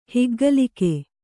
♪ higgalike